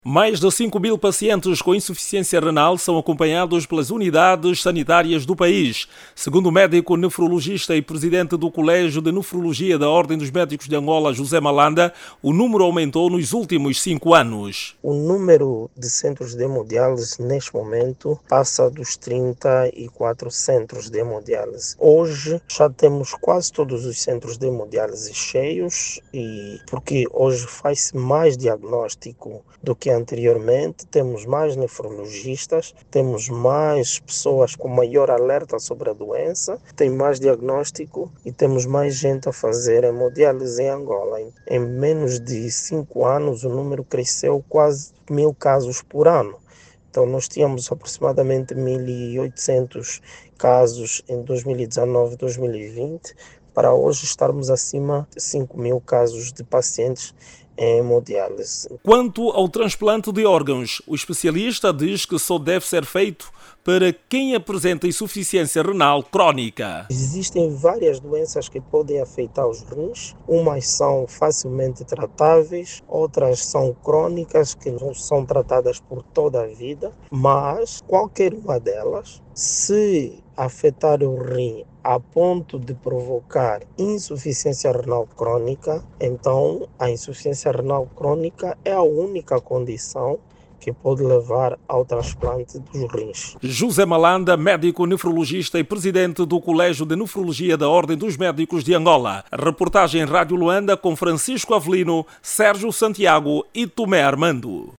Ouça o desenvolvimento desta matéria na voz do jornalista